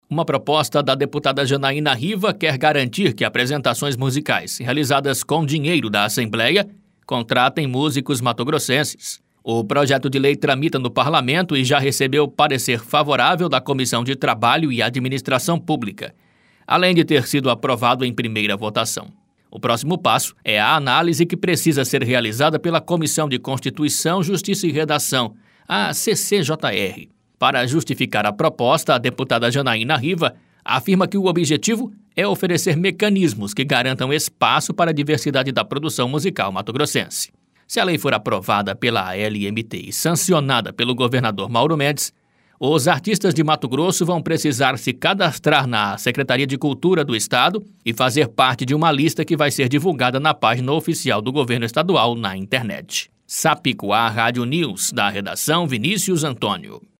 Boletins de MT 17 jun, 2022